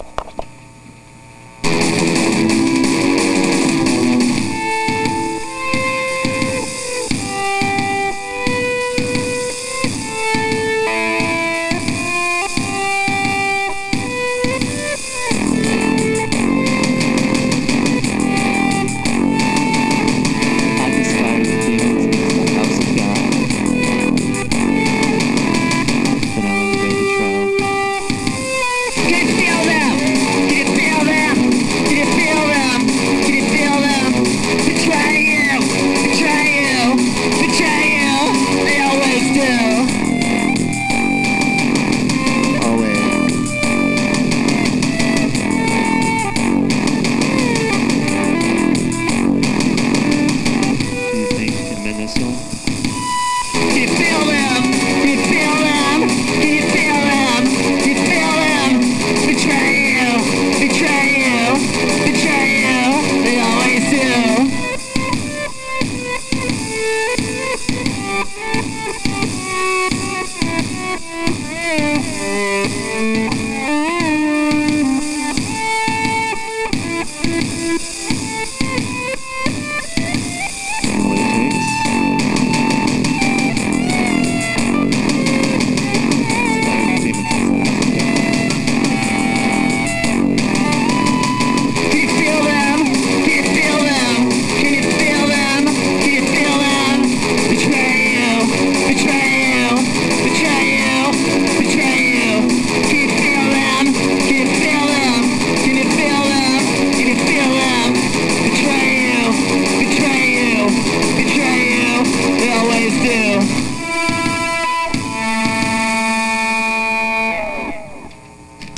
rationalhardcore double-album